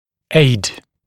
[eɪd][эйд]способствовать, помогать